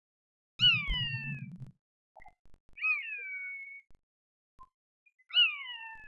fischio (286 KB) lamentoso e prolungato.
poiana.wav